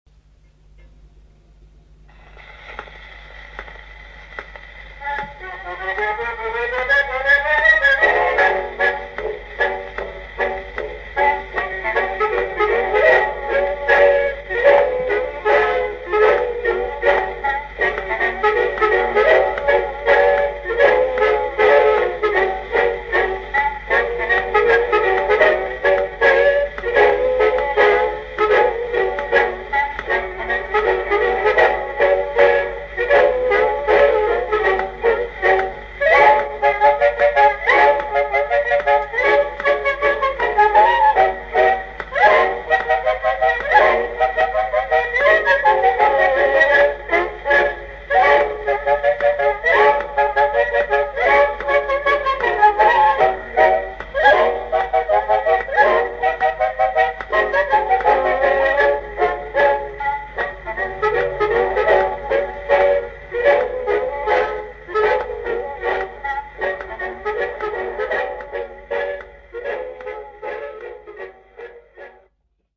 Tischgrammophon mit außenliegenden Messingtrichter
2 Feder Schneckenantriebsmotor
Glimmermembran-Schalldose
Aufgenommen mit Uher Report IC4000
Microfon Sennheiser MD421.2
Ton,Tischgrammophon Messingtrichter.mp3